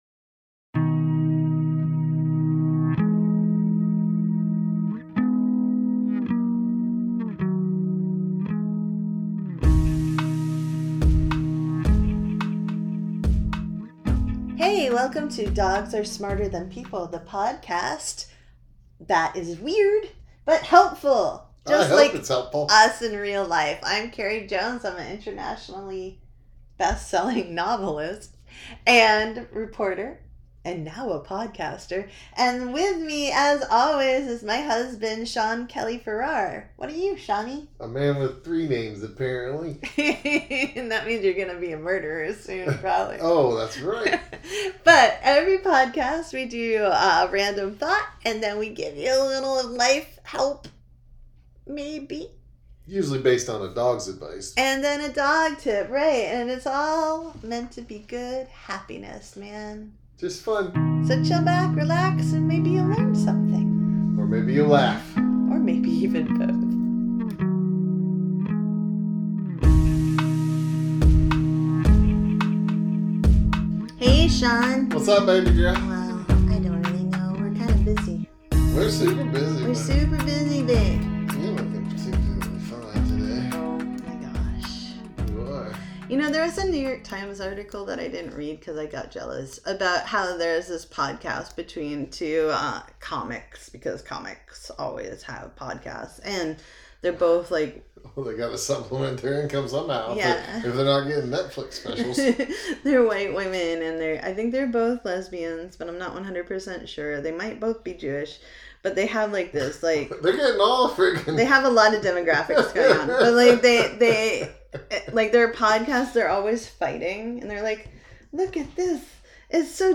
We improvised this podcast and you can tell.